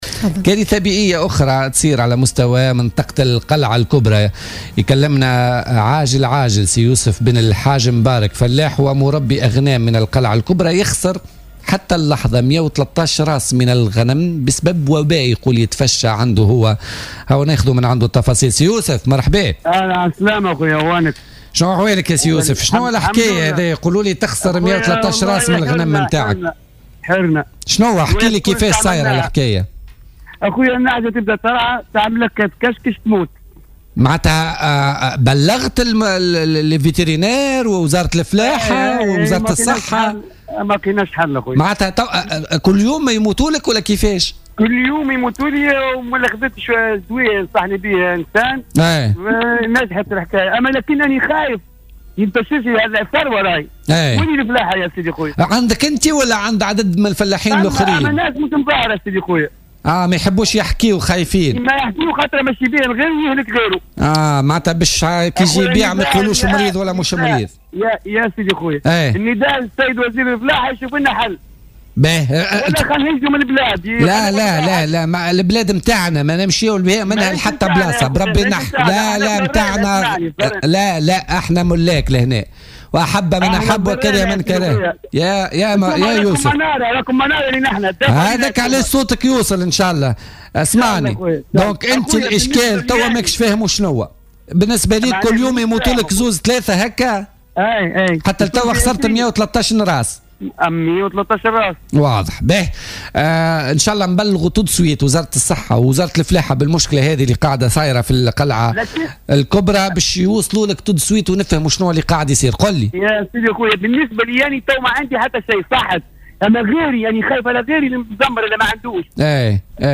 في اتصال هاتفي